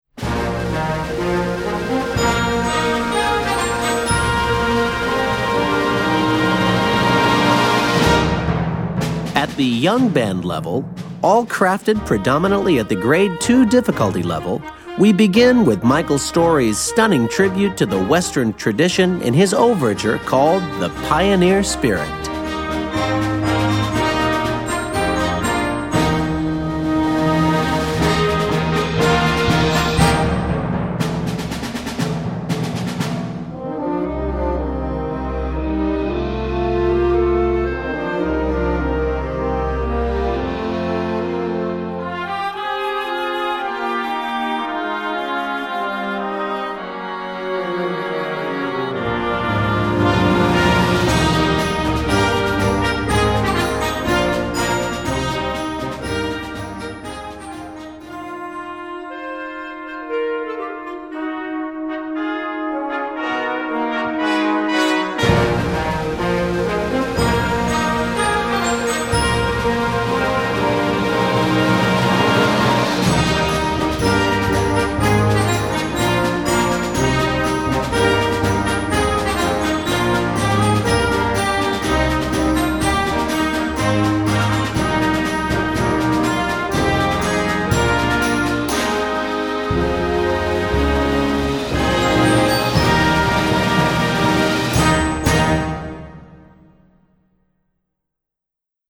Werk für Jugendblasorchester Schwierigkeit
3.27 Minuten Besetzung: Blasorchester PDF